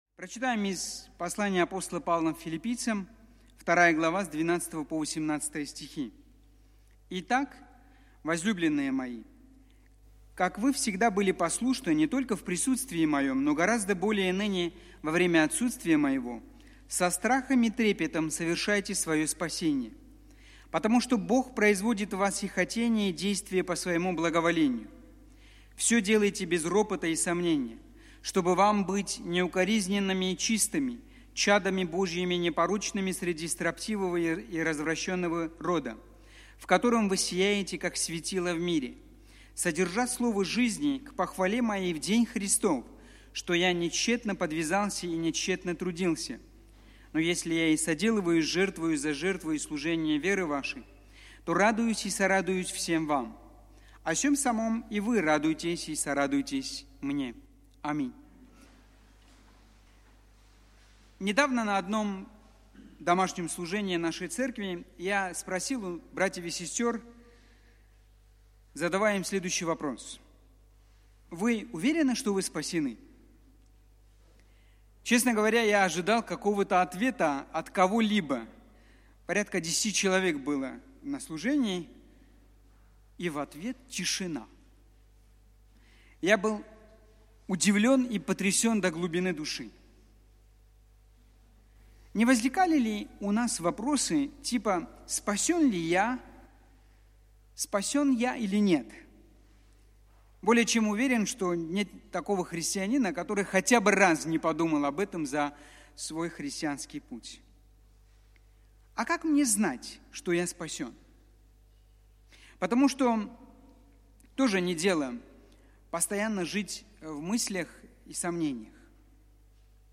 Проповеди
Сайт Московской Центральной церкви Евангельских христиан-баптистов.